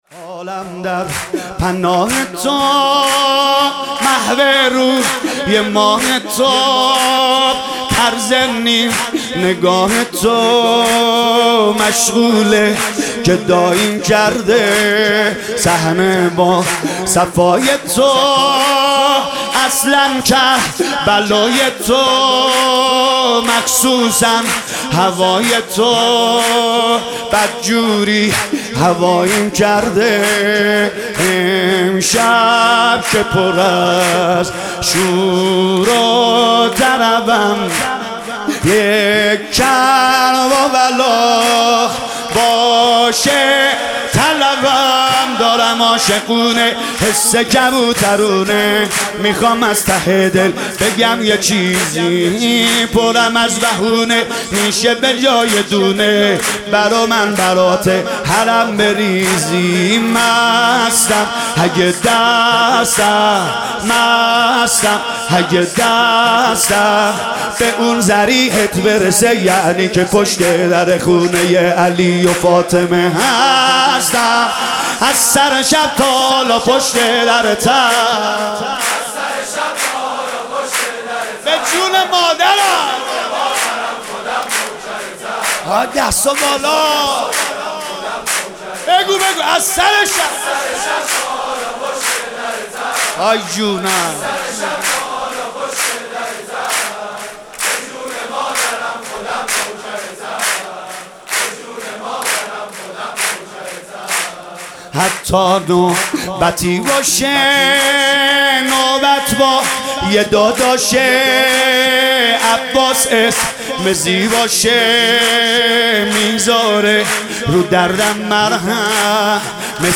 سرود: عالم در پناه تو محو روی ماه تو